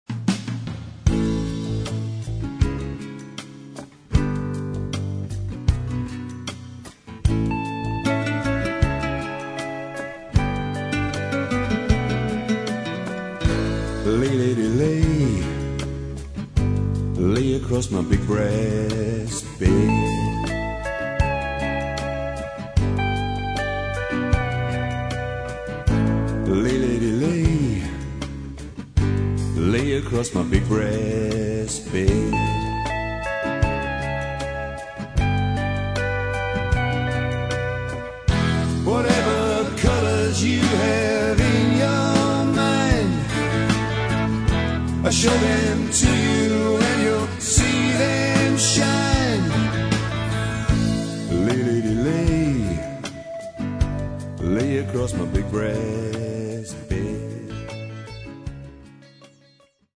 Рок
акустическая гитара, гитара, бас, клавиши, вокал